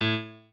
pianoadrib1_7.ogg